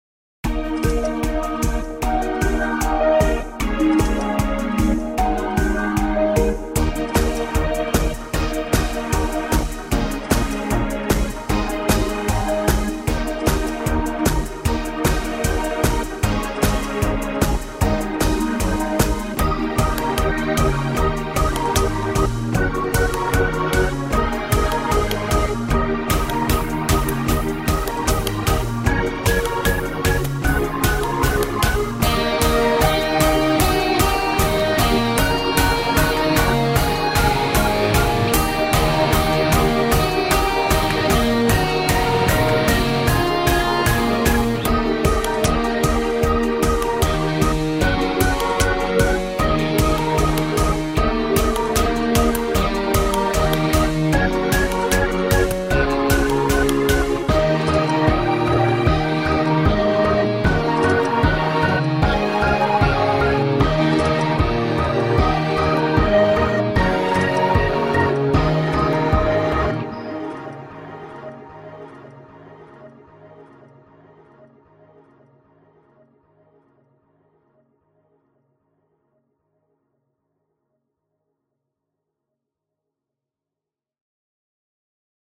Uplifting track for racing and sports.
Uplifting rock track for racing and sports.